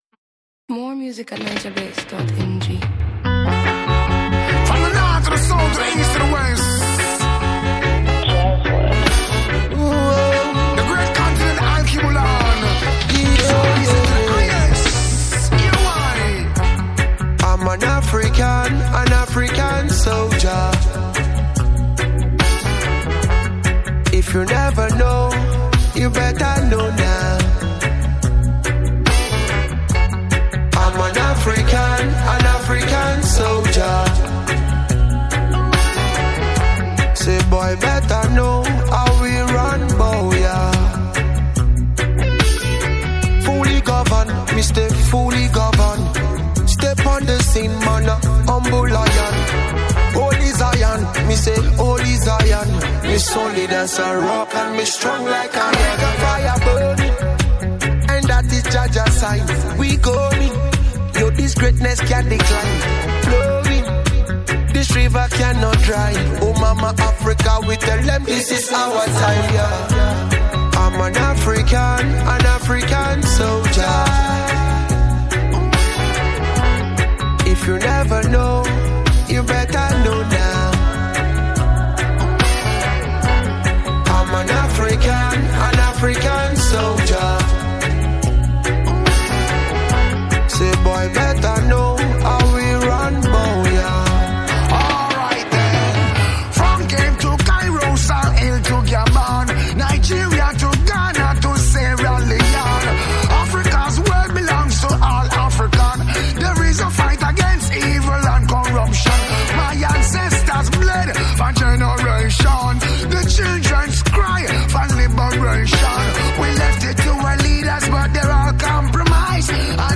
Nigerian reggae-dancehall